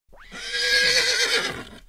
Neigh3.wav